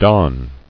[don]